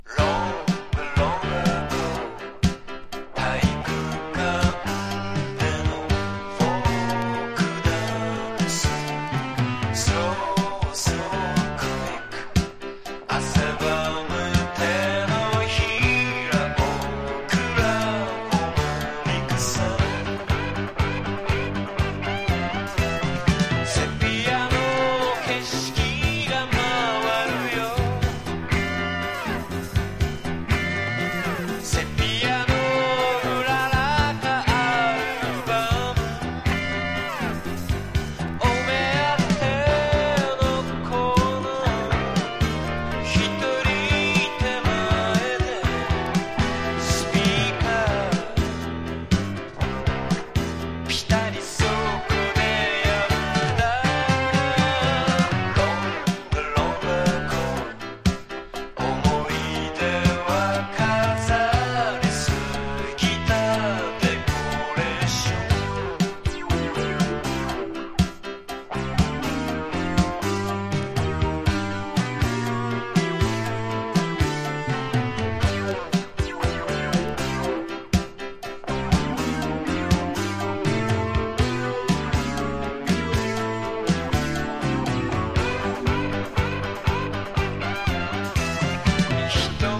SSW / FOLK# CITY POP / AOR# 和モノ